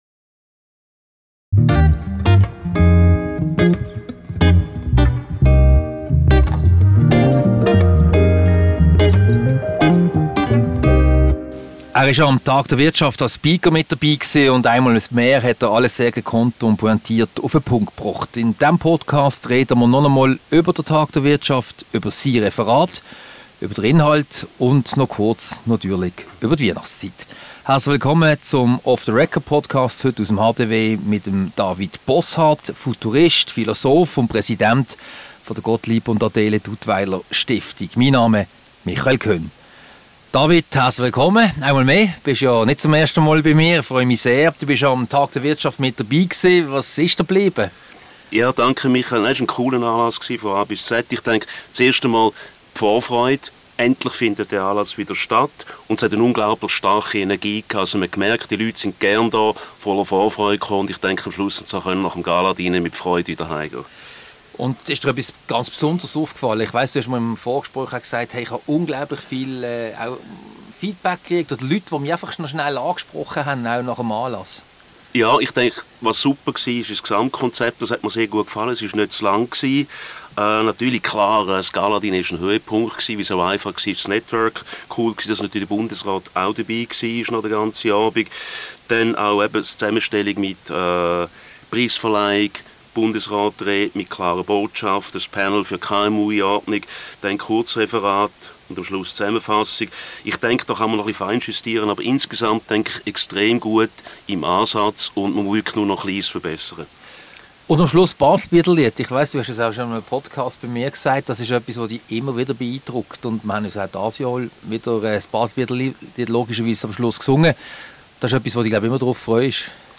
Ein Gespräch über den Tag der Wirtschaft, die Bühne und sein Referat an diesem Anlass.